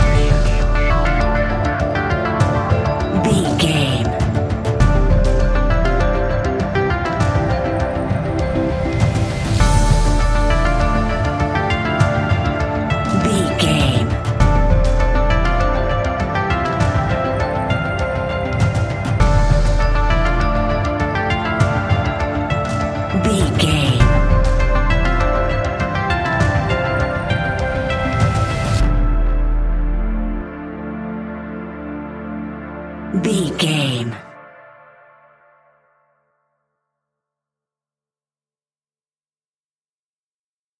Aeolian/Minor
D
scary
tension
ominous
dark
suspense
eerie
synthesiser
drums
percussion
horror music
horror instrumentals